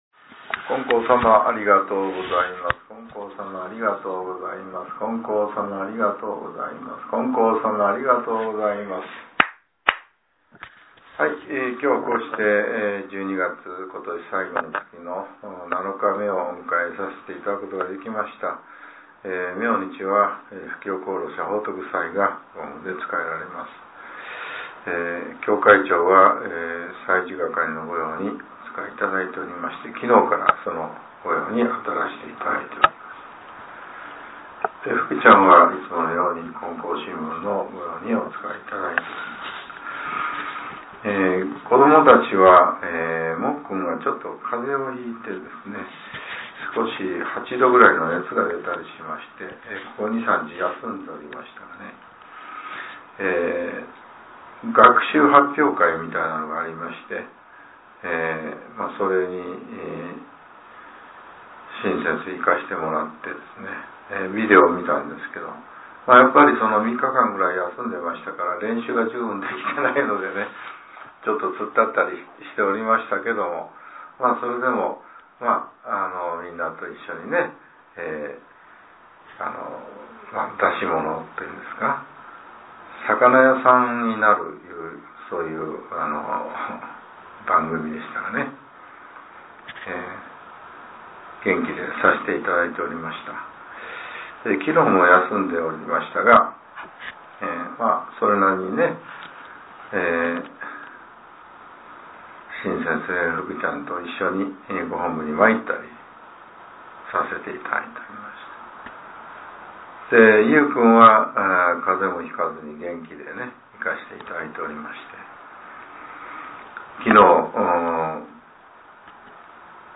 令和６年１２月７日（朝）のお話が、音声ブログとして更新されています。